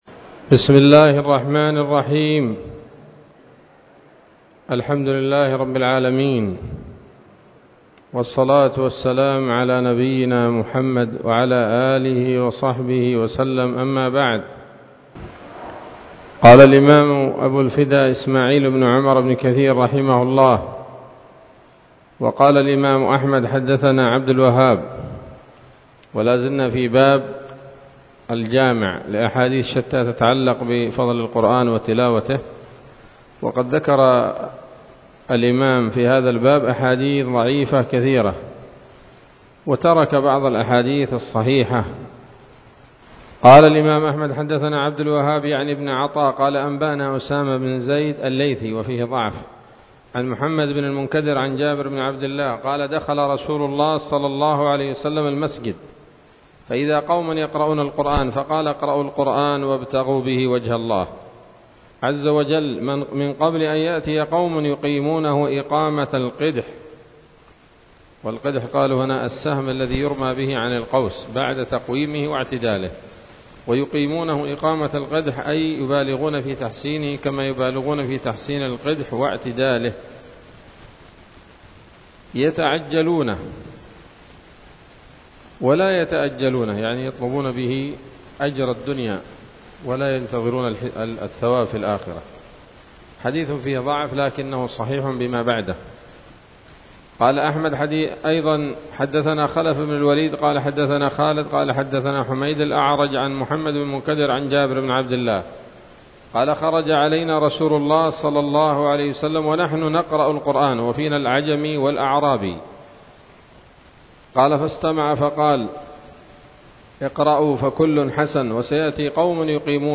الدرس الثاني والثلاثون من المقدمة من تفسير ابن كثير رحمه الله تعالى